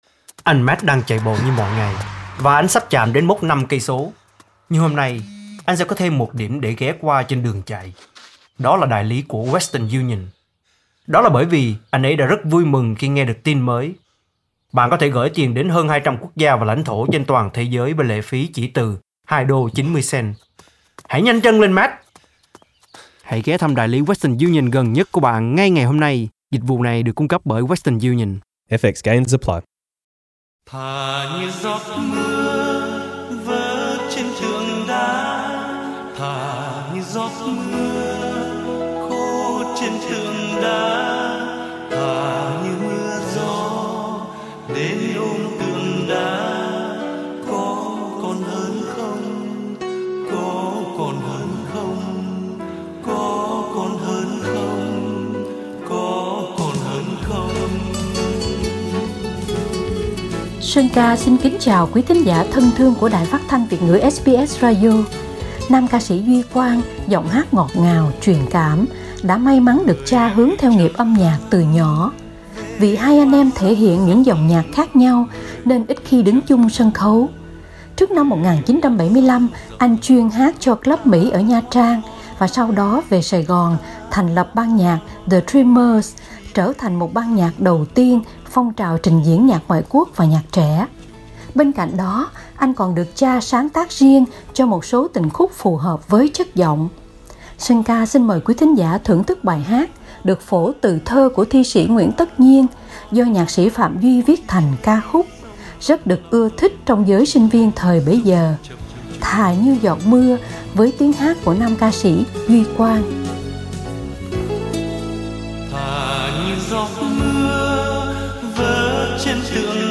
Ca sĩ, nhạc sĩ Duy Quang (1950-2012) sinh ra trong một đại gia đình nghệ sĩ: cha là nhạc sĩ Phạm Duy, mẹ là ca sĩ Thái Hằng, dì ruột là danh ca Thái Thanh. Mời quý thính cùng cùng nghe ca sĩ Sơn Ca giới thiệu thêm về đồng nghiệp Duy Quang.